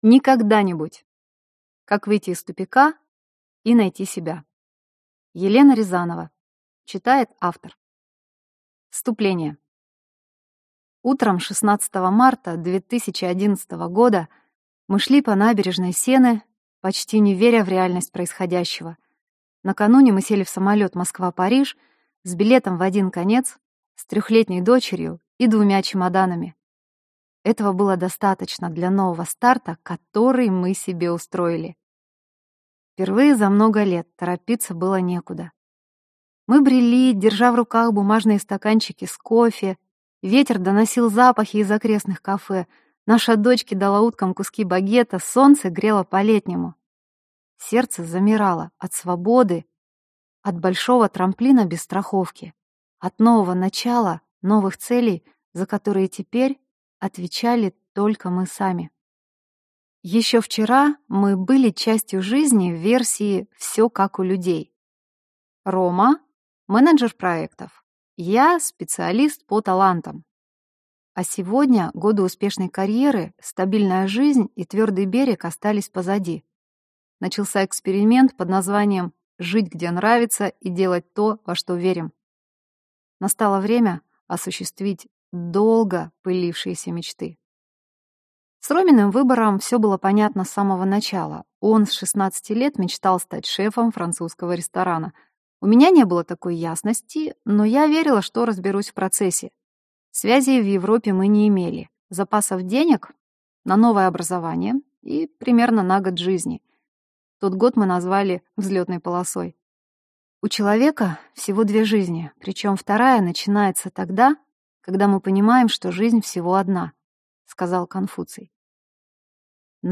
Аудиокнига Никогда-нибудь. Как выйти из тупика и найти себя | Библиотека аудиокниг